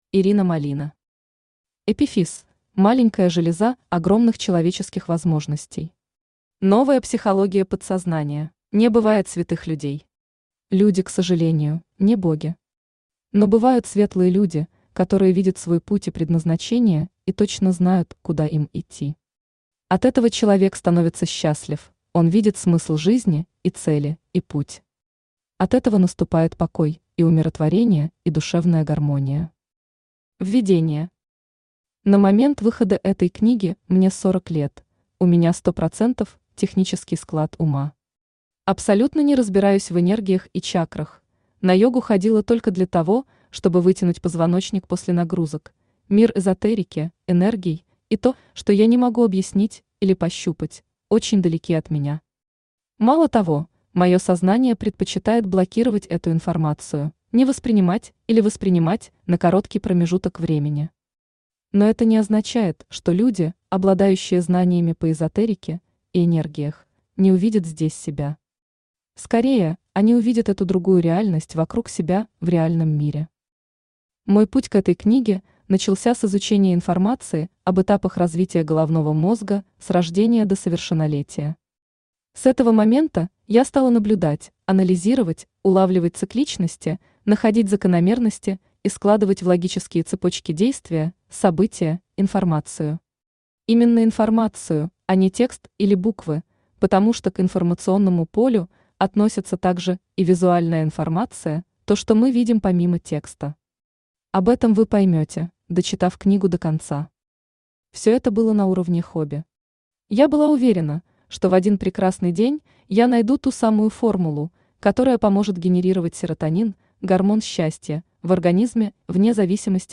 Аудиокнига Эпифиз – маленькая железа огромных человеческих возможностей. Новая психология подсознания | Библиотека аудиокниг
Новая психология подсознания Автор Ирина Малина Читает аудиокнигу Авточтец ЛитРес.